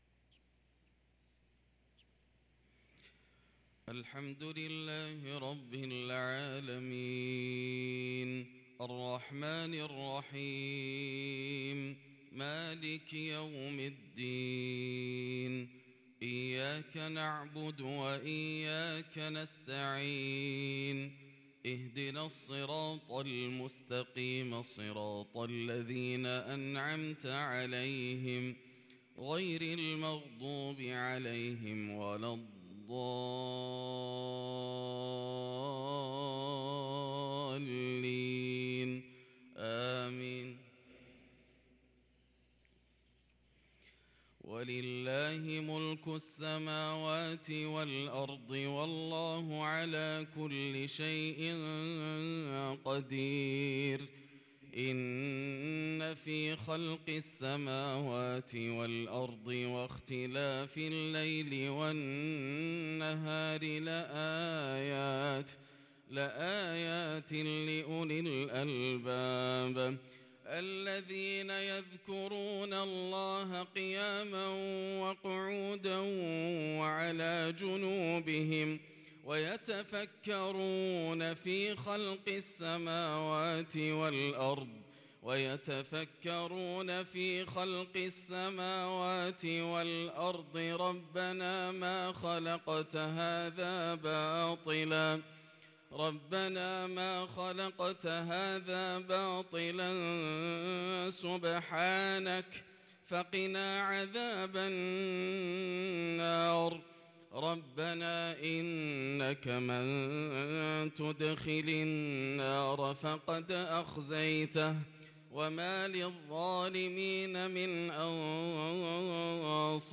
صلاة الفجر للقارئ ياسر الدوسري 29 ربيع الأول 1444 هـ
تِلَاوَات الْحَرَمَيْن .